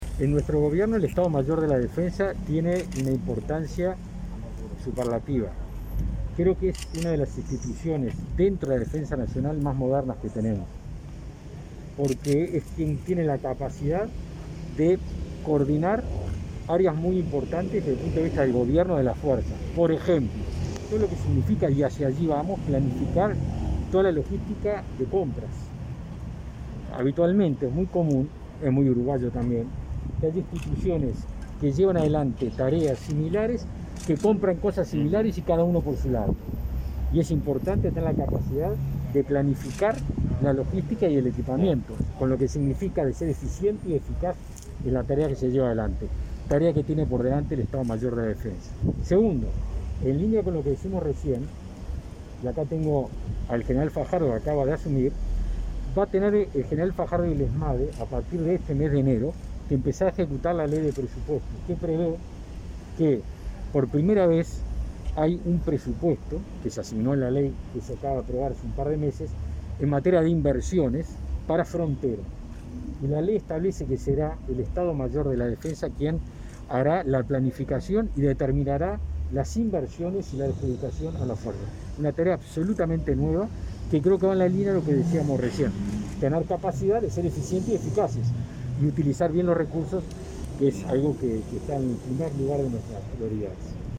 Declaraciones de Javier García, ministro de Defensa Nacional